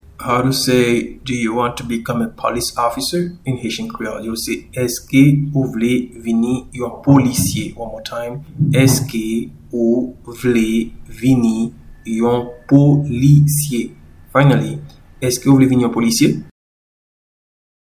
Pronunciation and Transcript:
Do-you-want-to-become-a-police-officer-in-Haitian-Creole-Eske-ou-vle-vini-yon-polisye.mp3